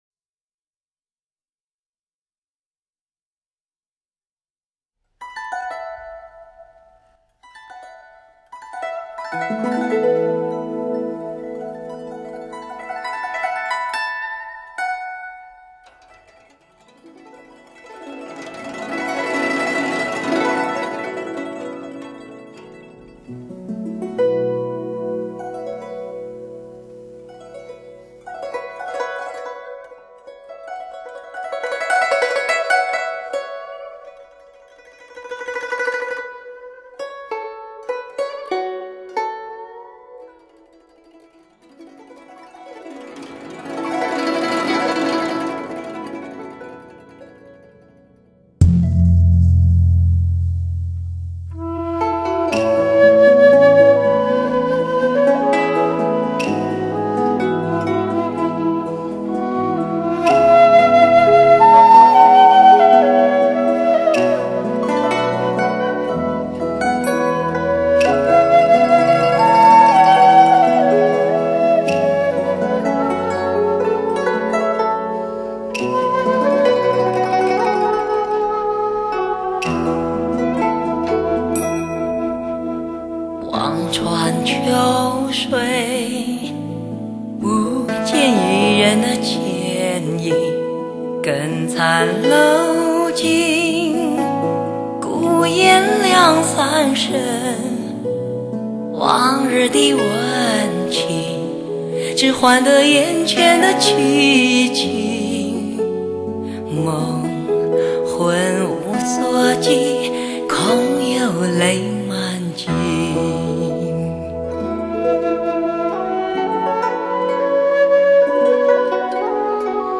资源类型：DTS音乐
DTS－ES6.1环绕音效超越现实主义的声音，带给您前所未闻的清晰，360度环绕的聆听享受。